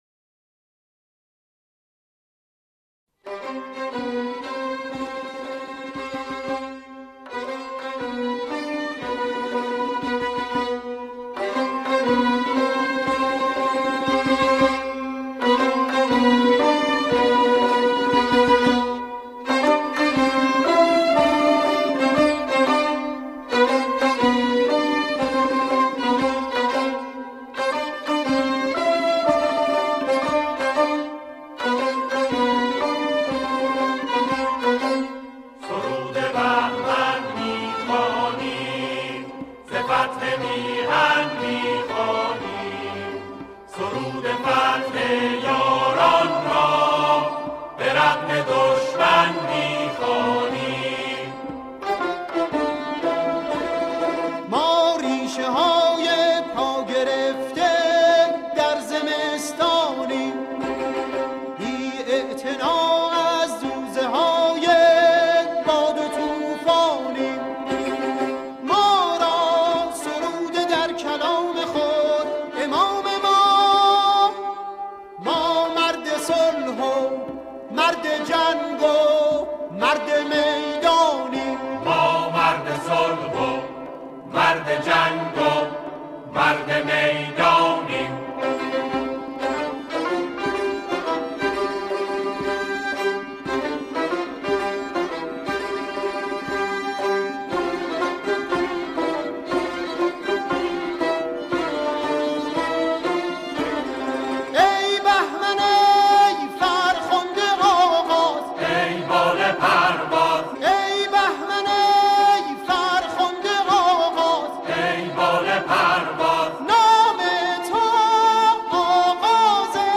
آنها در این قطعه، شعری را درباره دهه فجر همخوانی می‌کنند.